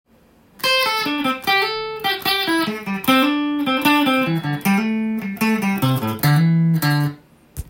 エレキギターで弾ける【歌うAマイナーペンタトニックスケール】シーケンスパターン【オリジナルtab譜】つくってみました
【歌うAマイナーペンタトニックスケール】シーケンスパターン